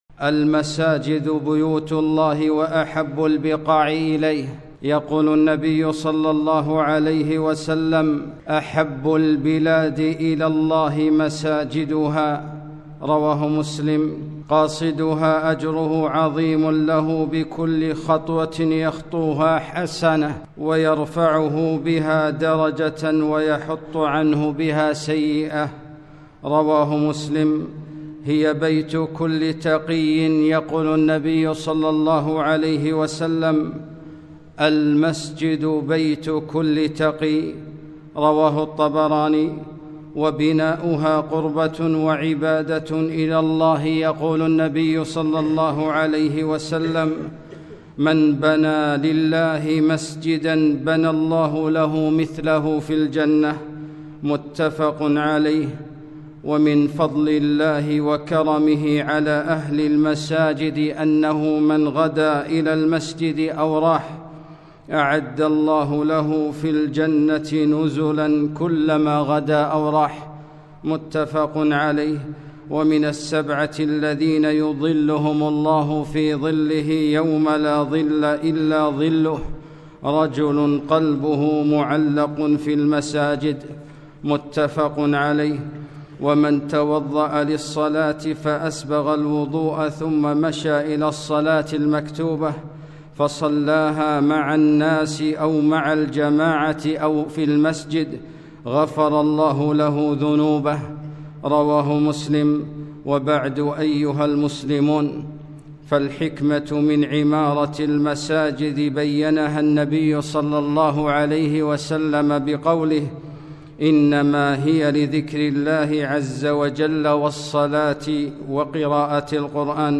خطبة - فضل عمارة المساجد وآدابها